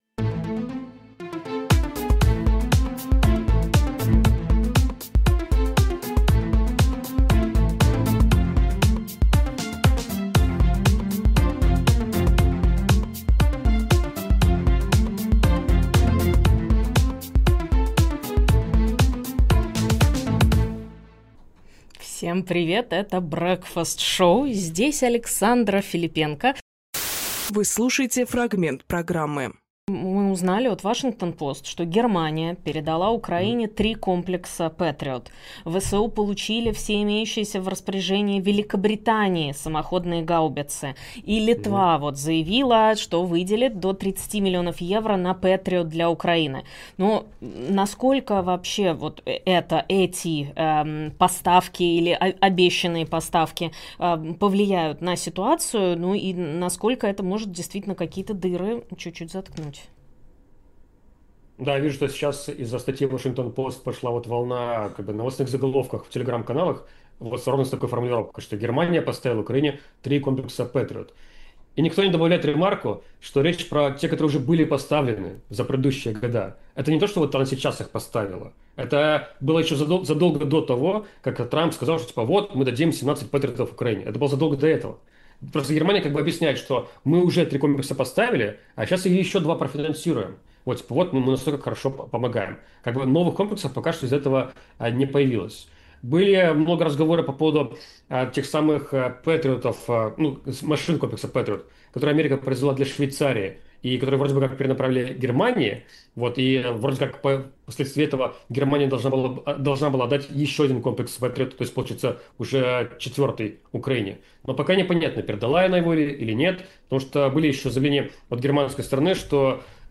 Фрагмент эфира от 27.07.25